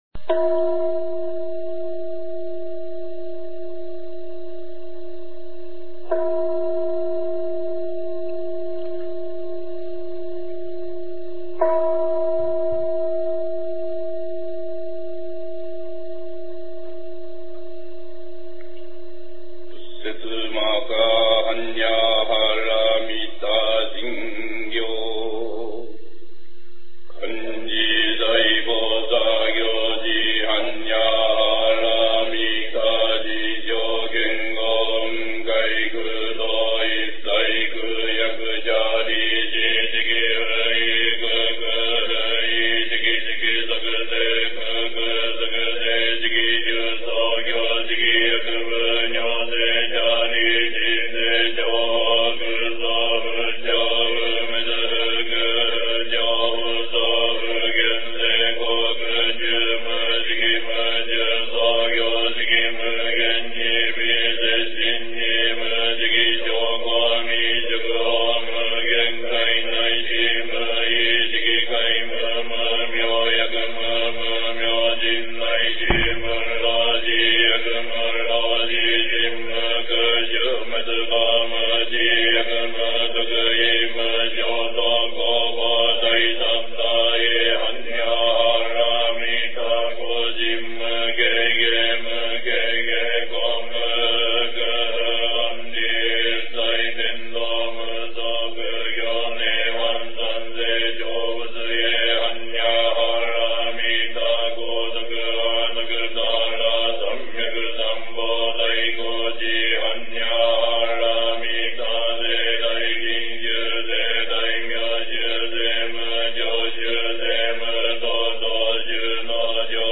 Recited in original laguage "Mahahannyaharamita